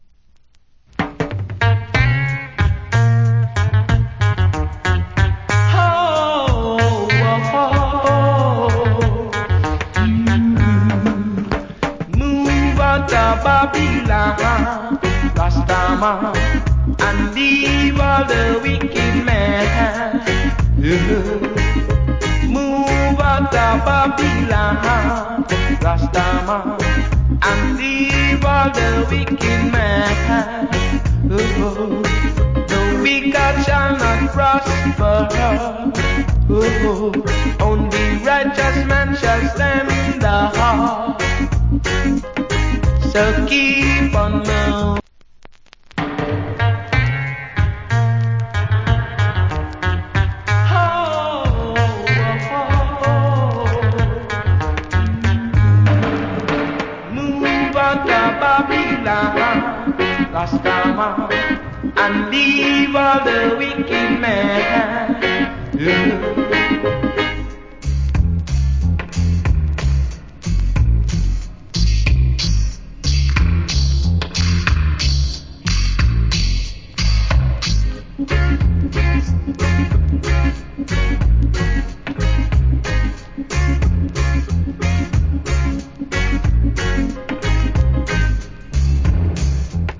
Roots Rock Vocal.
/ Good Dub.